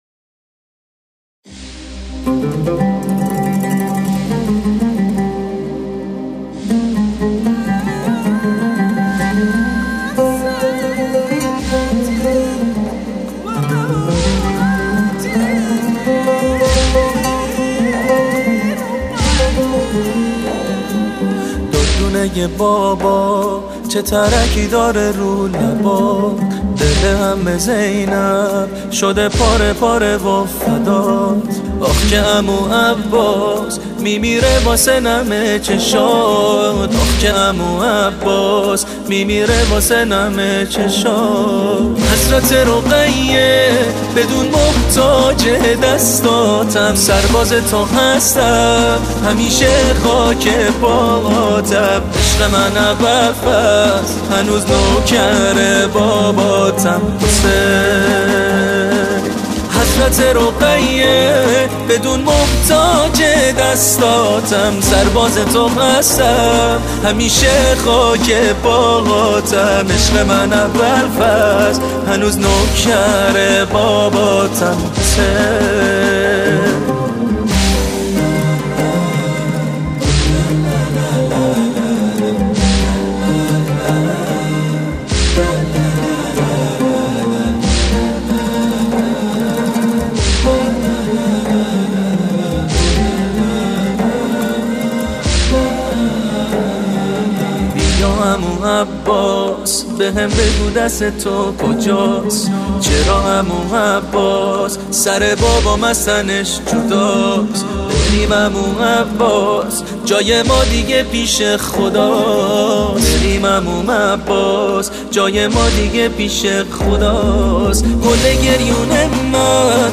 عود